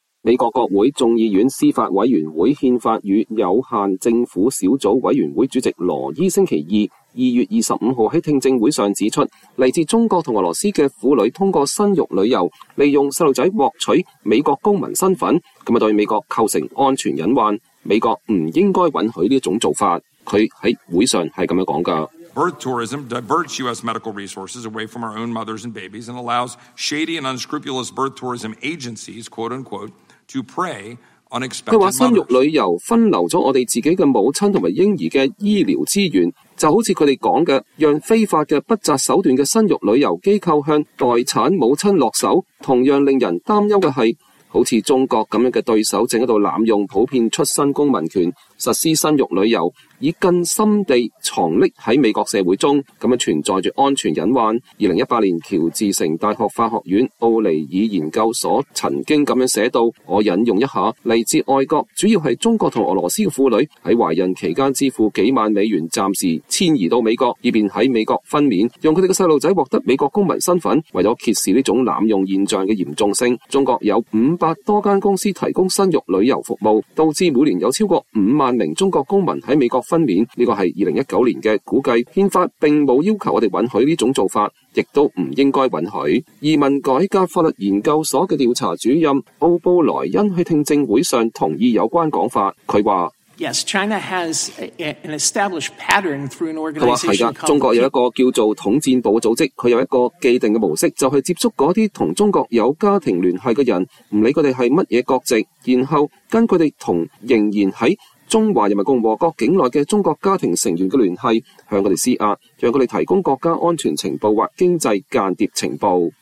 美國國會眾議院司法委員會憲法與有限政府小組委員會主席羅伊(Chip Roy)週二(2月25日)在聽證會上指出，來自中國和俄羅斯的婦女通過生育旅遊，利用孩子獲取美國公民身份。